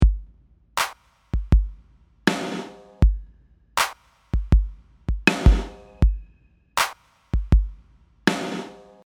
In my example I’m using a fairly long attack time so the reverb almost feels like it’s swelling in from the snare hit, giving it an inhaling sound.
The point here is to make the snare sound like it’s breathing.
Here’s what I end up with.